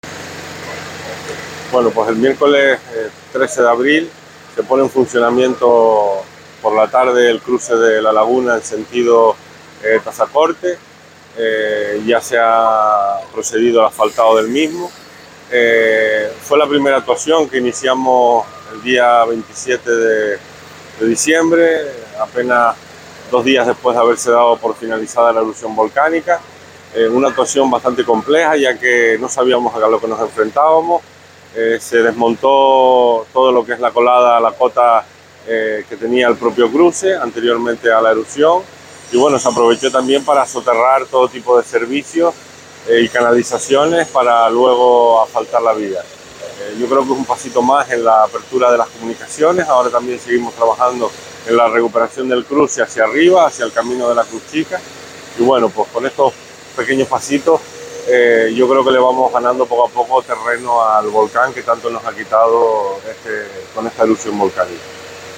Declaraciones Borja Perdomo audio.mp3